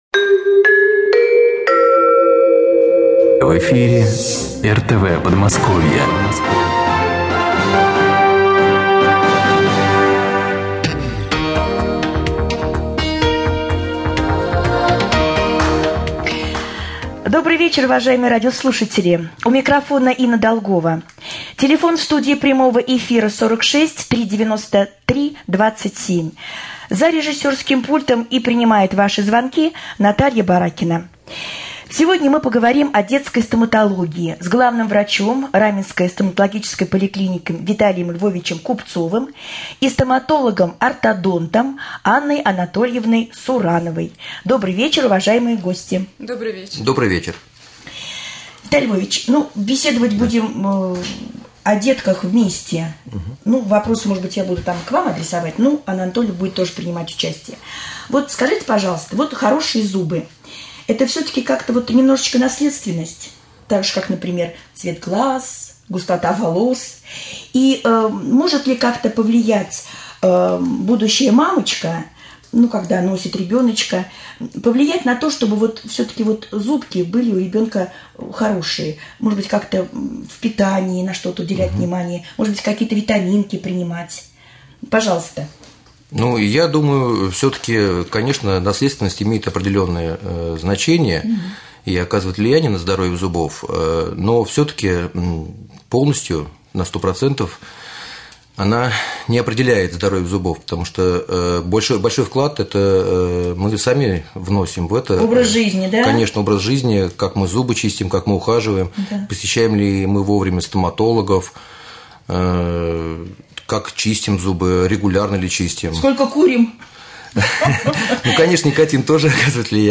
Эфир-стоматологи.mp3